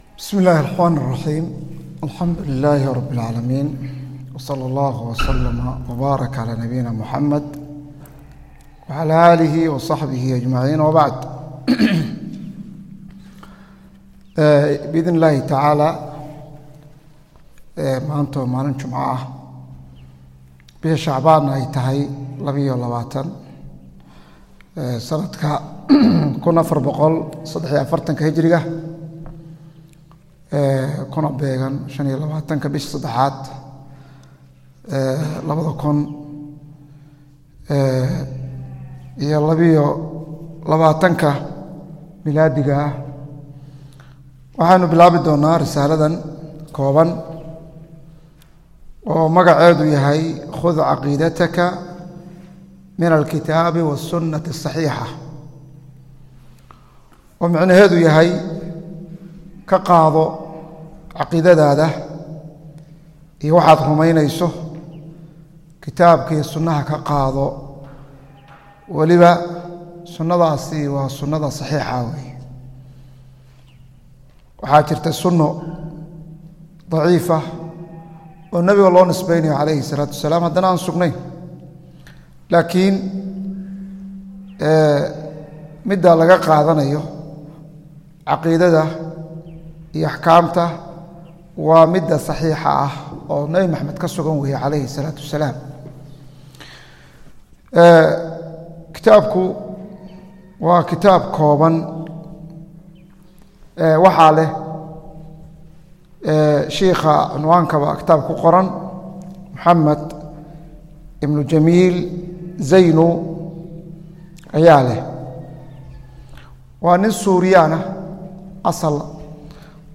Sharaxa Kitaabka Khud Caqiidatak - Darsiga 1aad - Manhaj Online |